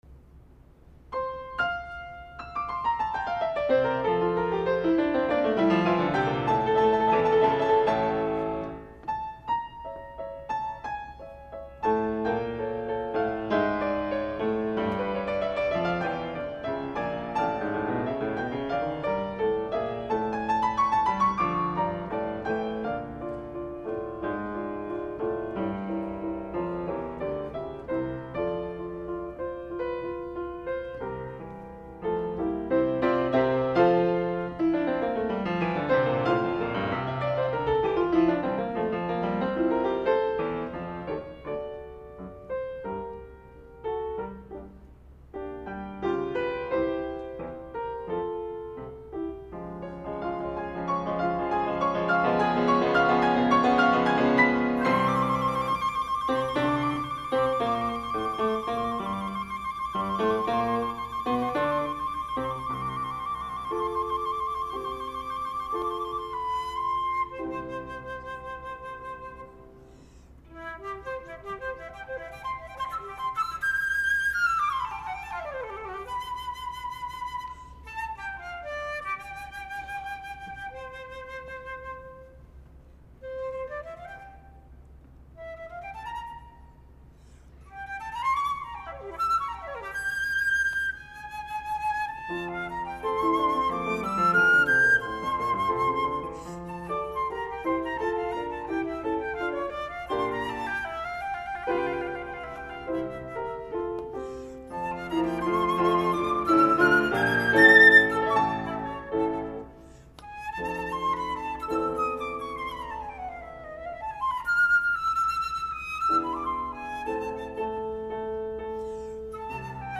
In this recording, she's playing a Yamaha flute with a "K-cut" head-joint.?